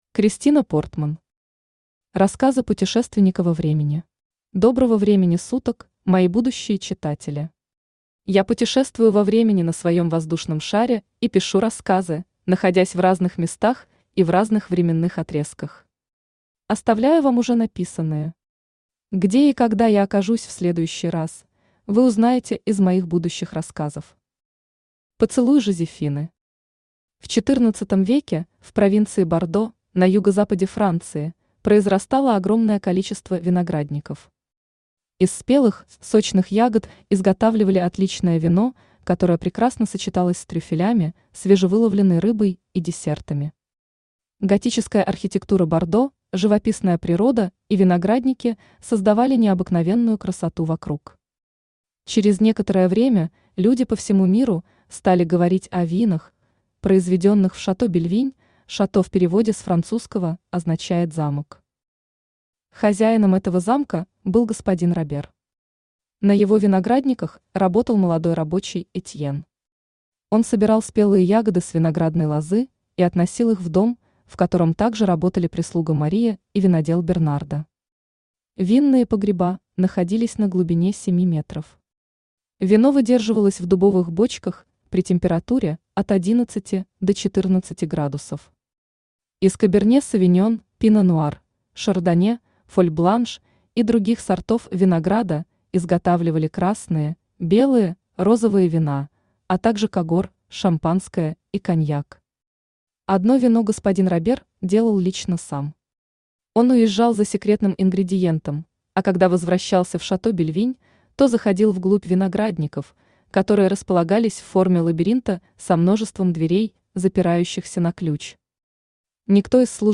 Аудиокнига Рассказы путешественника во времени | Библиотека аудиокниг